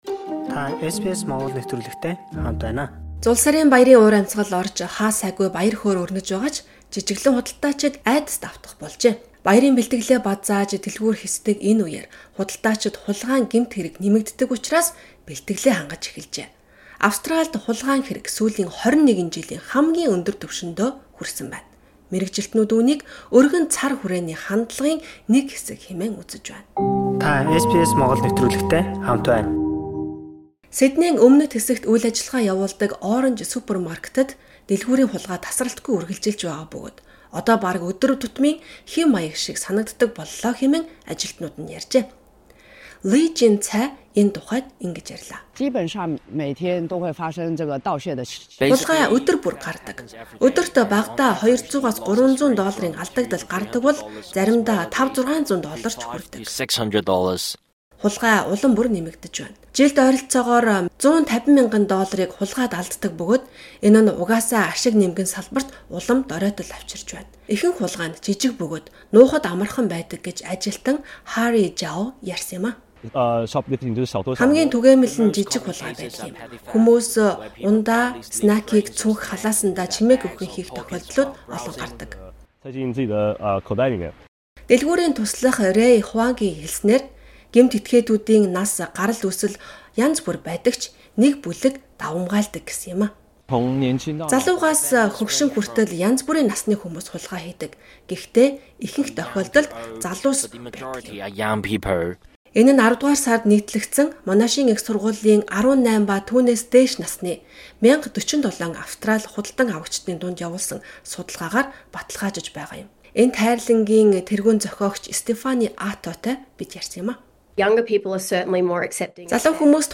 МЭДЭЭ: Баяр дөхөхийн хэрээр хулгайн хэрэг нэмэгдсээр байна